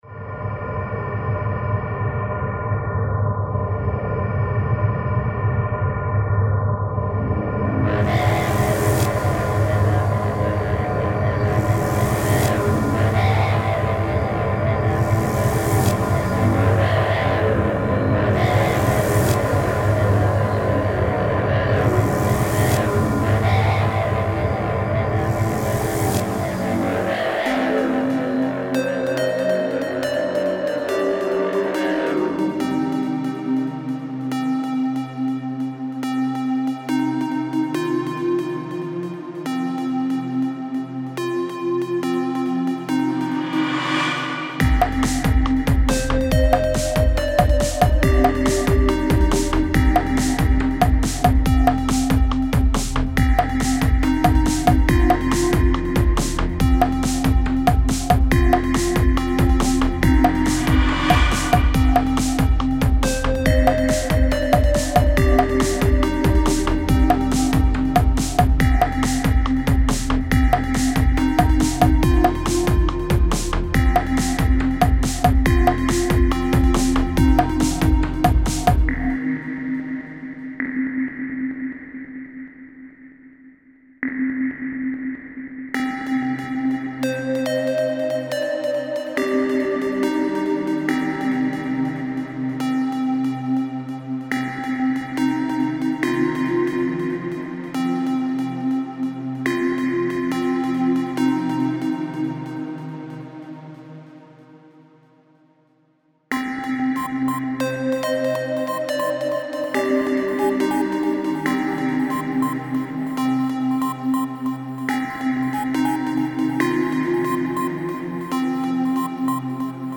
Experimental Ambient